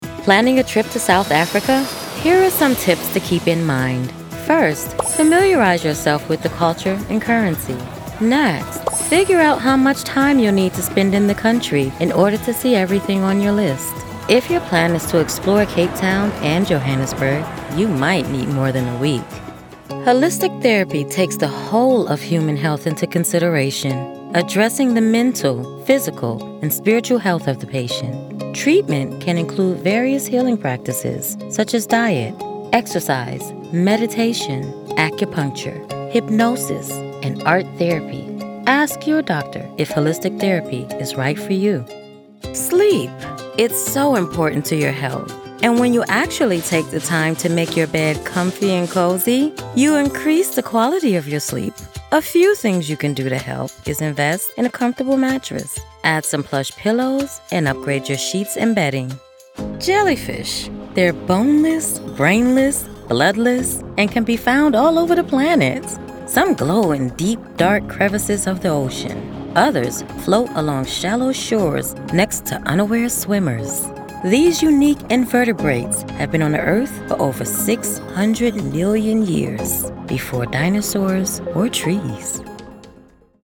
Dynamic in personality that shines through regardless of what the topic is.
Narration demo samples
Middle Aged